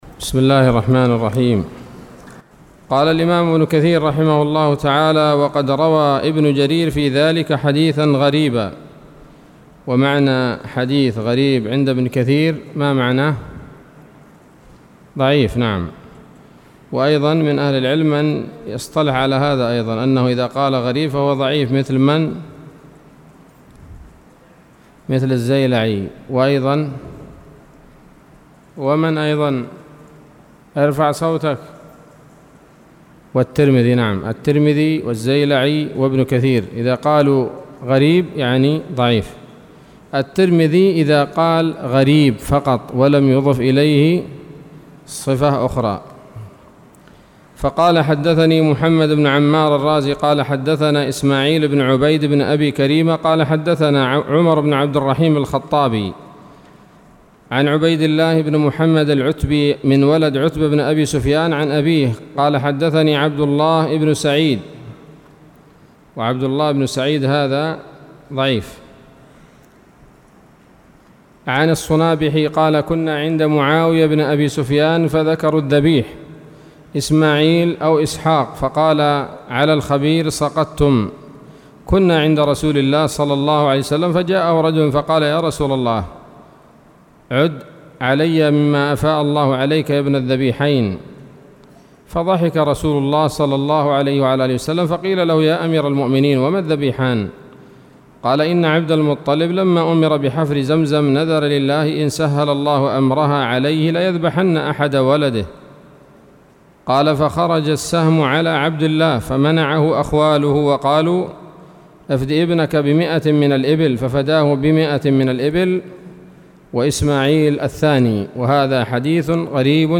الدرس الحادي عشر من سورة الصافات من تفسير ابن كثير رحمه الله تعالى